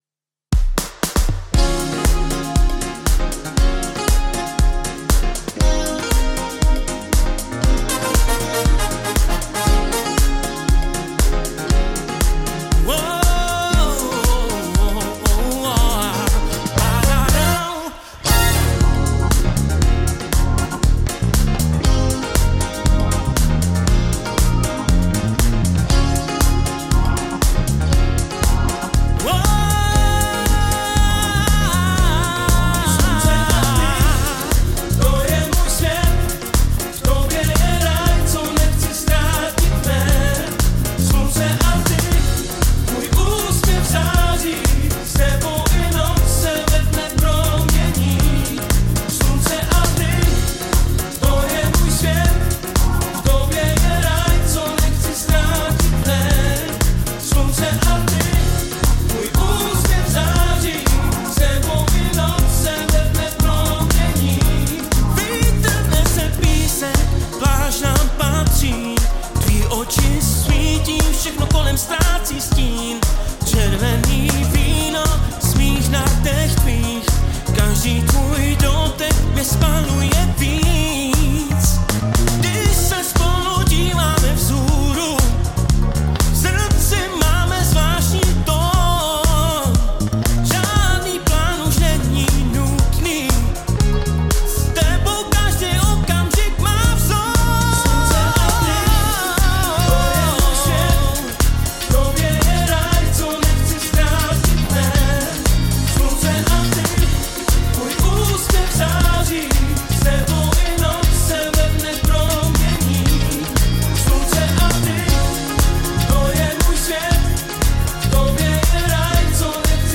We are Gipsy Wind, a live band from Europe, and we are looking for a mix & mastering engineer for our upcoming releases. Style: Disco / Funk / Jazz / Rock (influences: Incognito, Jamiroquai, Luis Miguel) Band instrumentation: Drums, Bass, Electric Guitar, 2× Keyboards, Trumpet, Sax, Trombone, Lead Vocals. We are looking for someone who can combine: • Vintage analog vibe (70s/80s) • Modern pun ... chy vocals and clarity All stems are clean and professionally recorded — great arrangements and vibe, we just need the final touch.